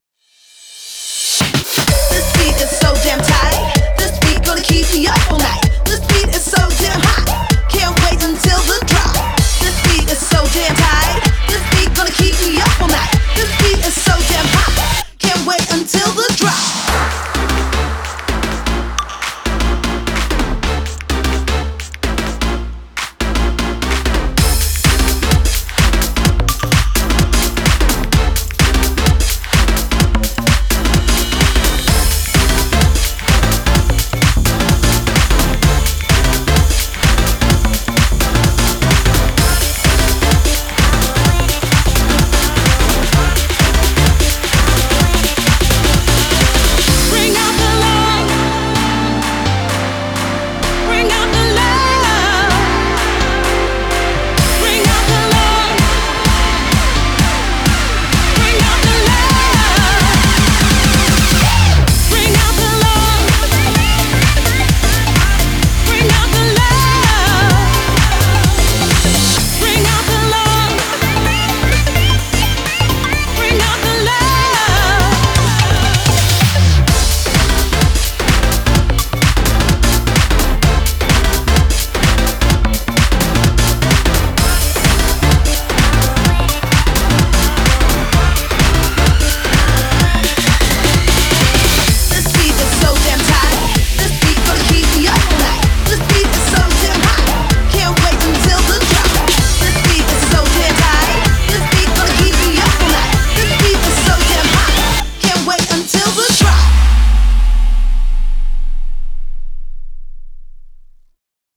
BPM128